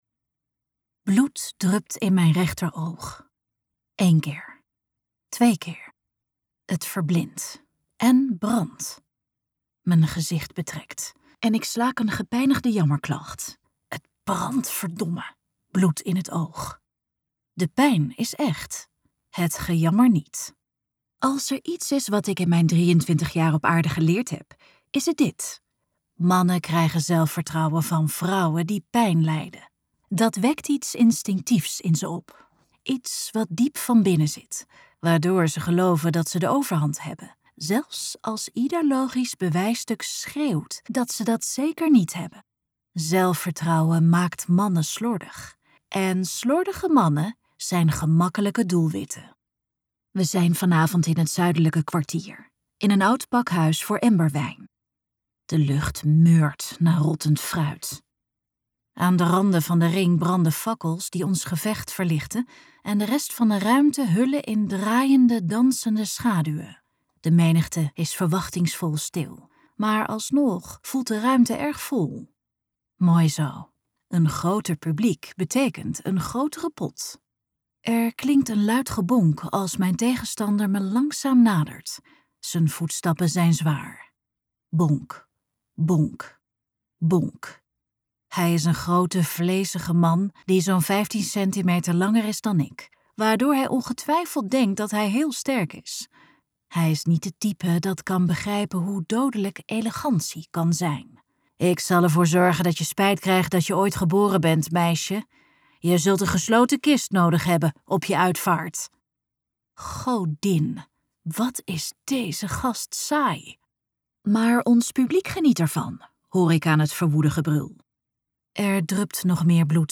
Ambo|Anthos uitgevers - Dire bound luisterboek